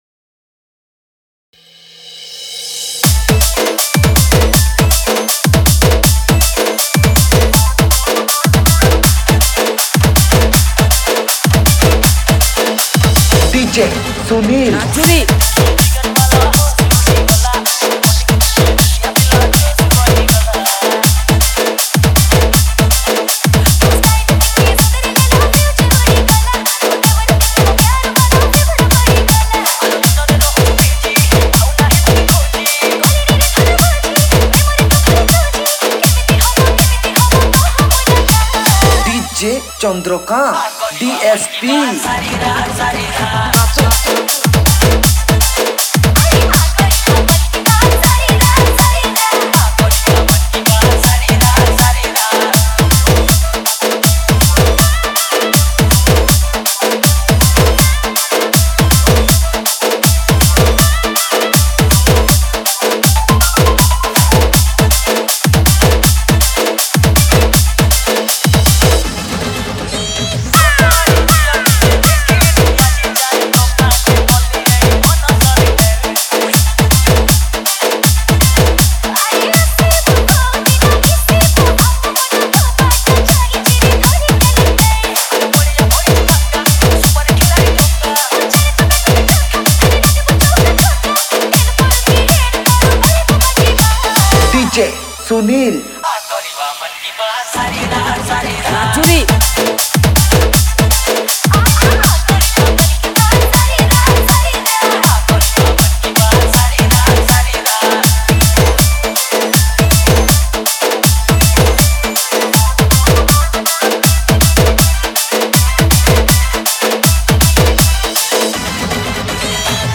Category:  New Odia Dj Song 2020